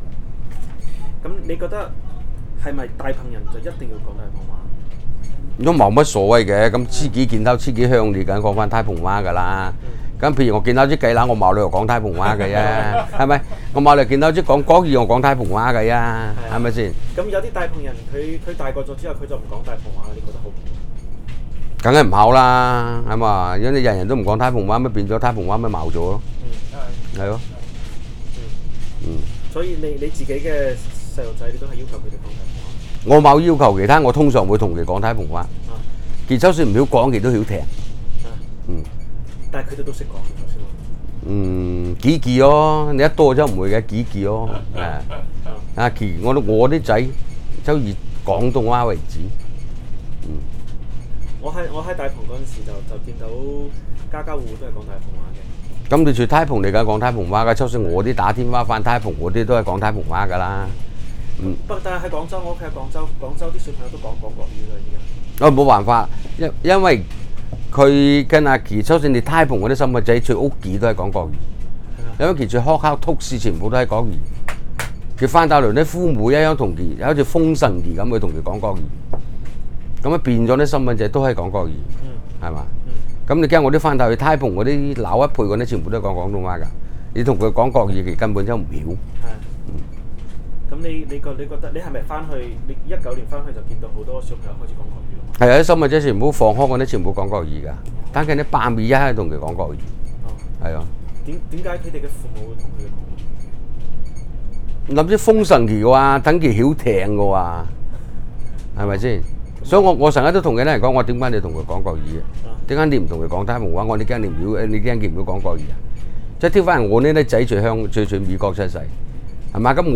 Male, 57
Male, 73
digital wav file recorded at 44.1 kHz/16 bit on Zoom H2 solid state recorder
Dapeng dialect in Shenzhen, China